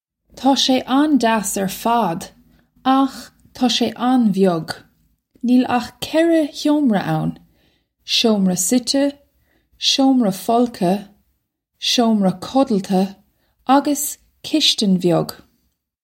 Taw shay an-jass air fad, akh taw shay on-vy-ug. Neel akh ceh-rah hyo-mra ow-n: showm-ra sit-eh, showm-ra full-ka, showm-ra kuddulta, a-gus kish-tin vy-ug.
This is an approximate phonetic pronunciation of the phrase.